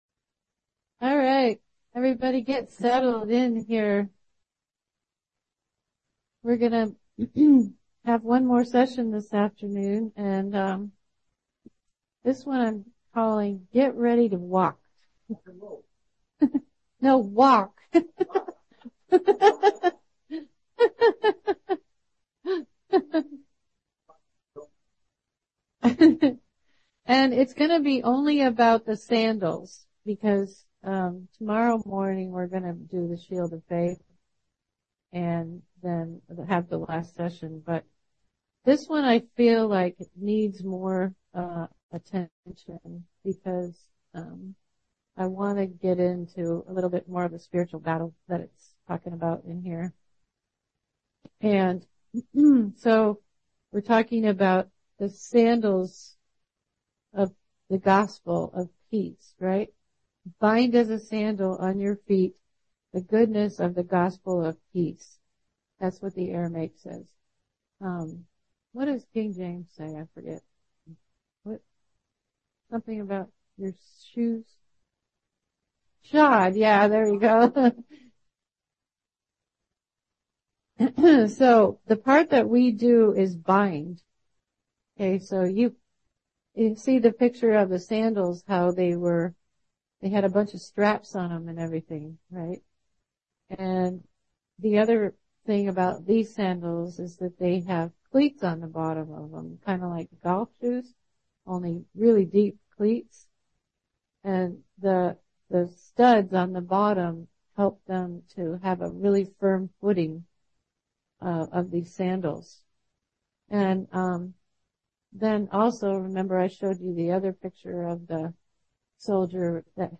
Armor of God Seminar 2024 Part 4 cat-aog